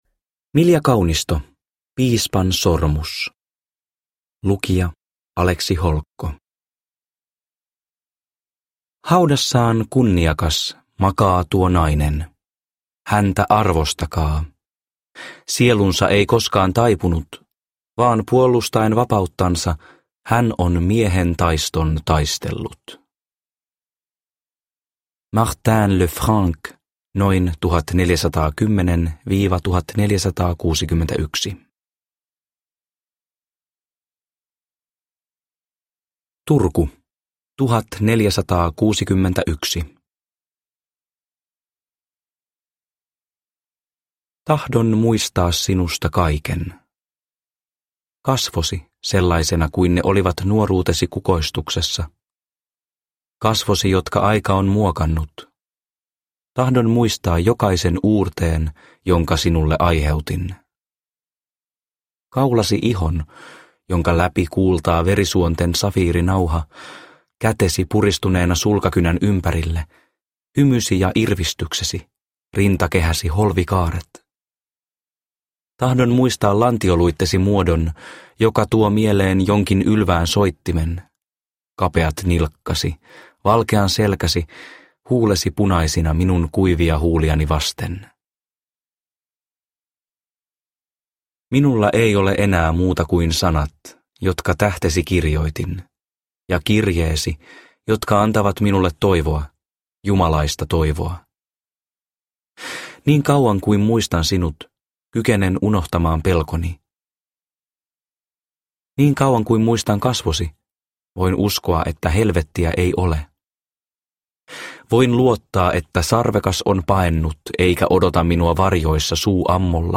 Piispansormus – Ljudbok – Laddas ner